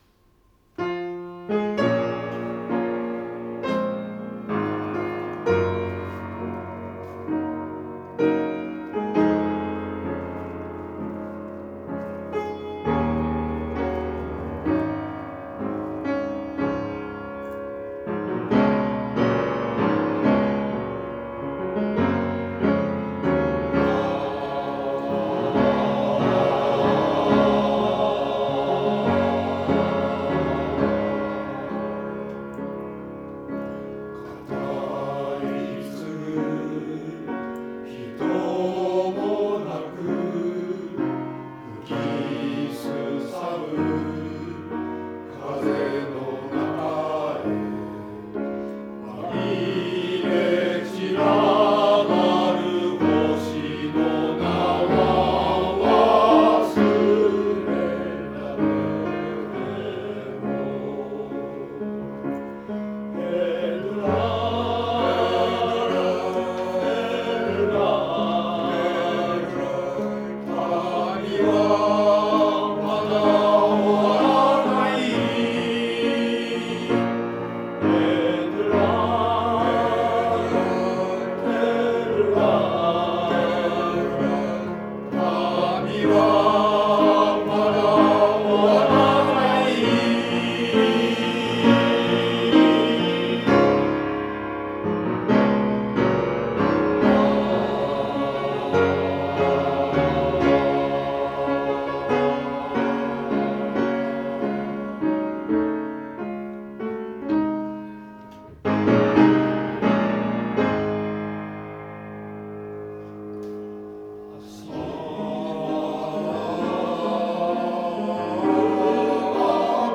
合唱祭が近づく、湖北台市民センターでの練習